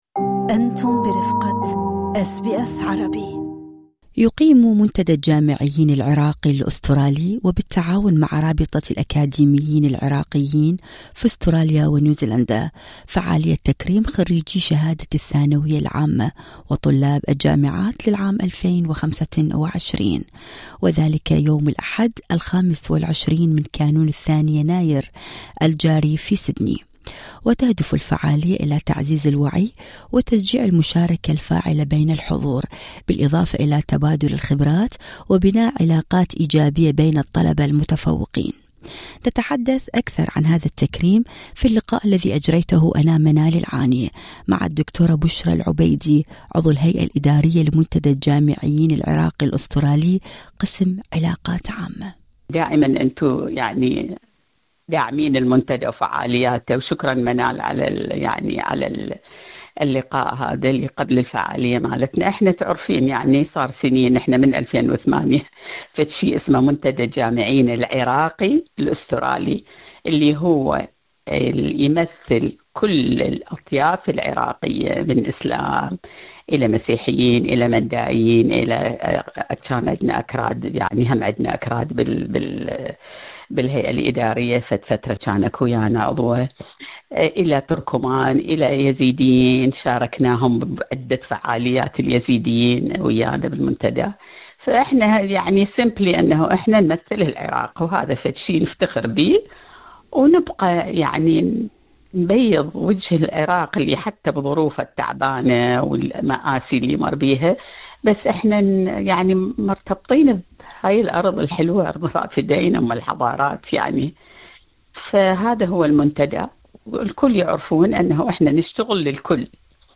نتحدث أكثر عن هذا التكريم في اللقاء الصوتي